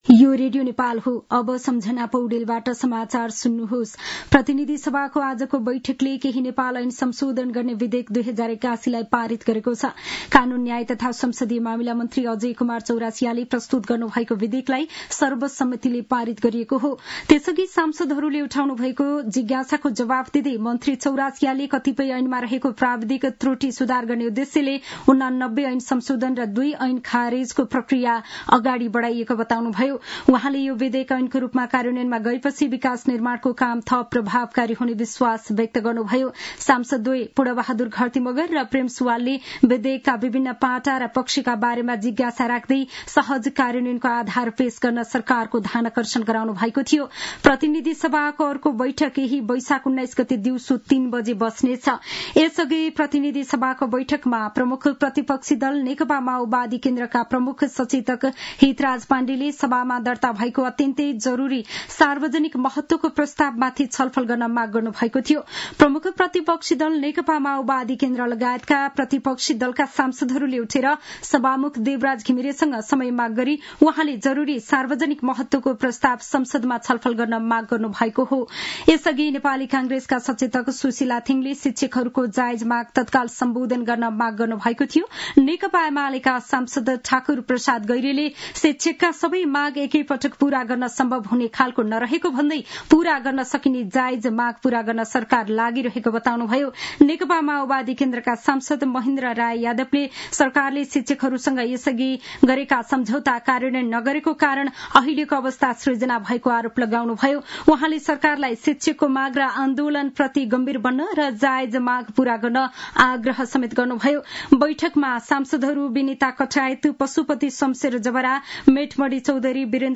दिउँसो ४ बजेको नेपाली समाचार : १६ वैशाख , २०८२
4-pm-news-1.mp3